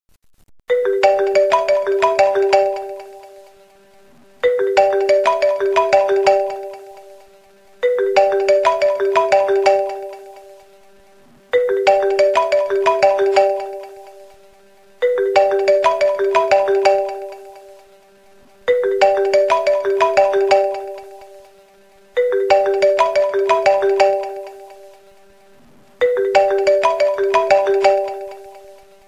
iPhone Ringtones